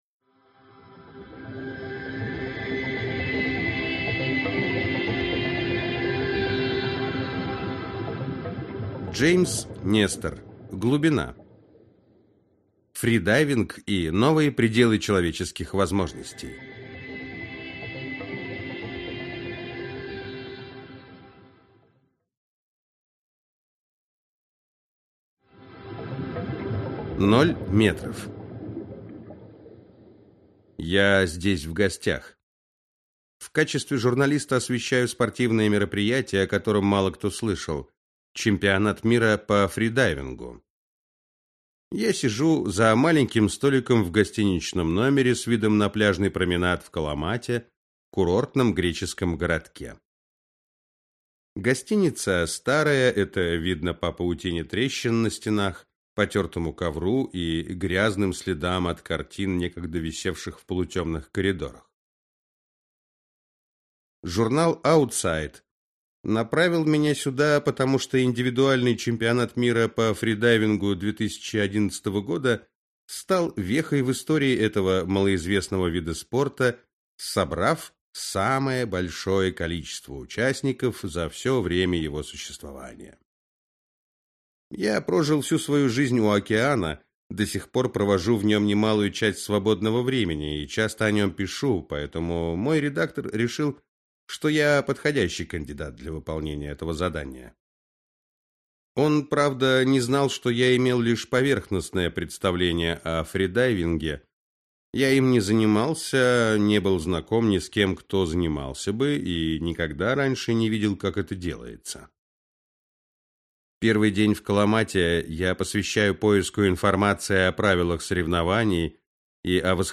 Аудиокнига Глубина. Фридайвинг и новые пределы человеческих возможностей | Библиотека аудиокниг
Прослушать и бесплатно скачать фрагмент аудиокниги